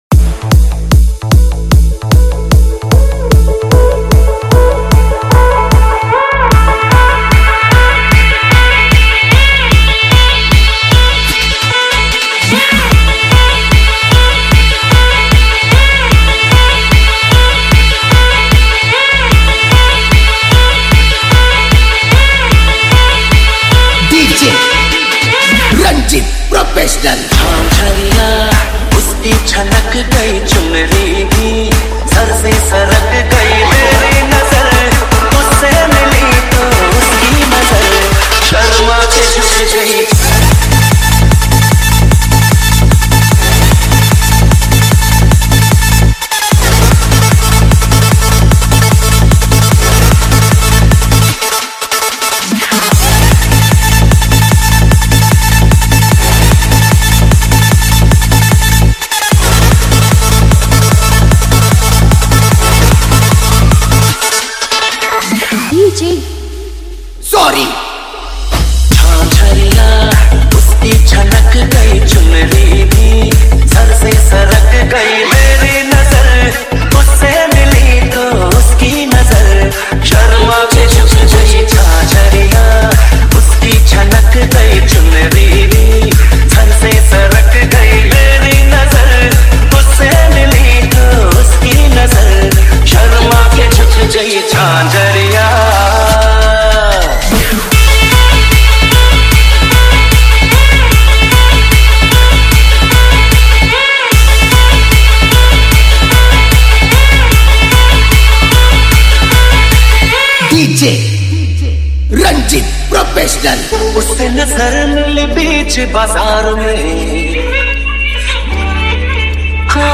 Category : Old Is Gold Remix Song